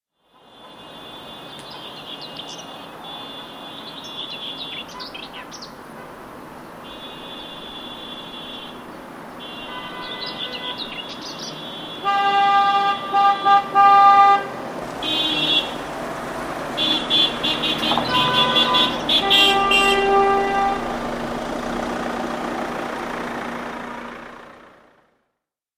Гудки машин в свадебном кортеже